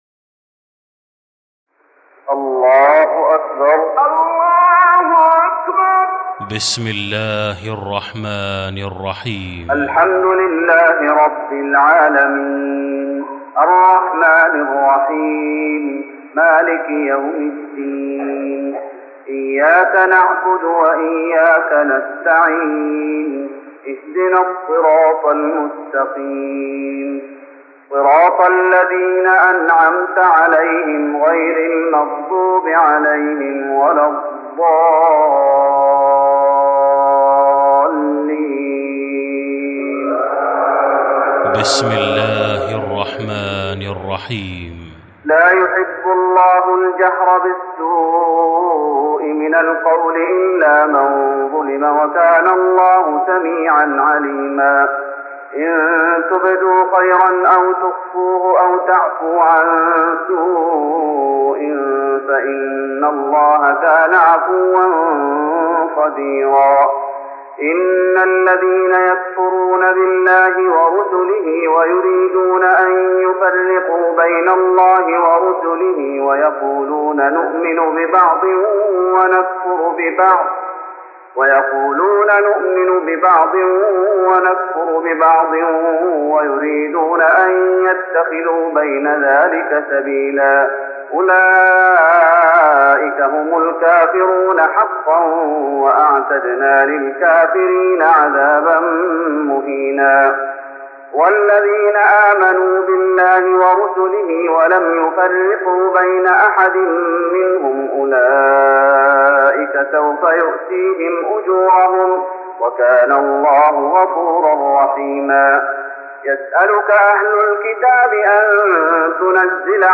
تراويح رمضان 1414هـ من سورة النساء (148-176) Taraweeh Ramadan 1414H from Surah An-Nisaa > تراويح الشيخ محمد أيوب بالنبوي 1414 🕌 > التراويح - تلاوات الحرمين